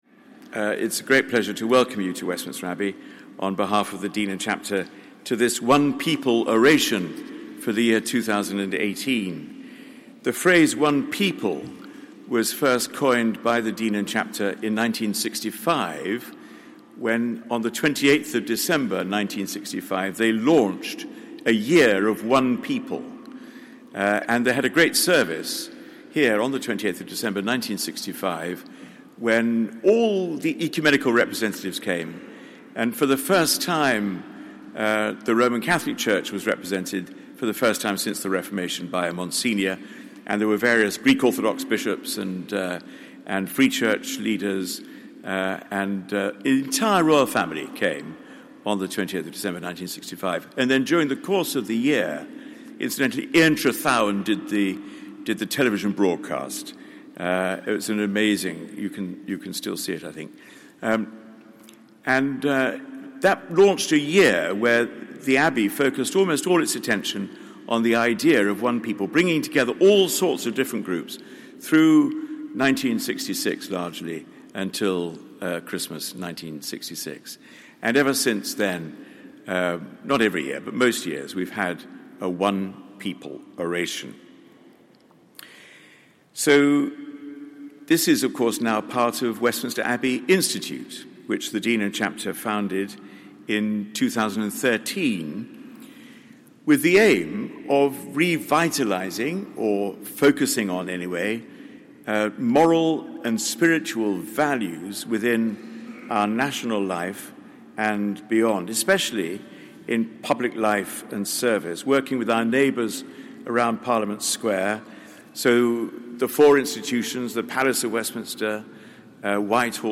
The Rt Hon Baroness Scotland of Asthal QC, Secretary-General of the Commonwealth gives the One People Oration 2018 on the theme of Embracing Global Challenges. Chair: The Very Reverend Dr John Hall, Dean of Westminster.